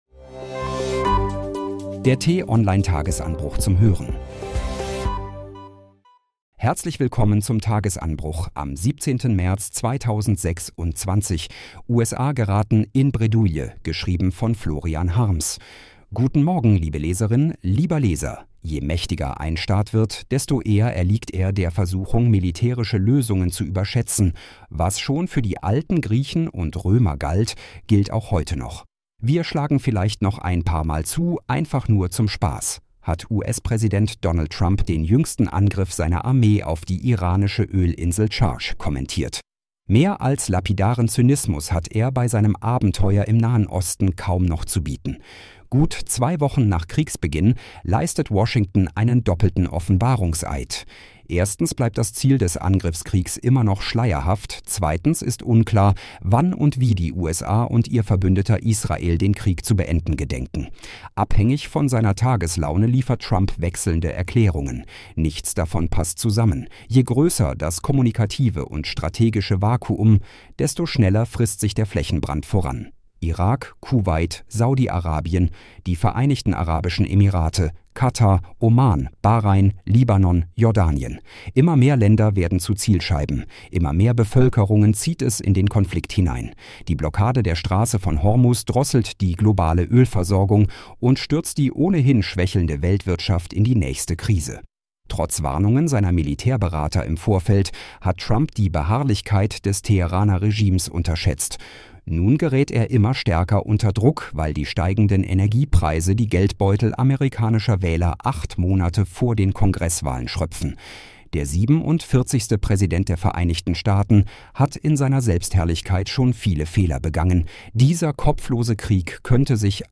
Nachrichten
zum Start in den Tag vorgelesen von einer freundlichen KI-Stimme –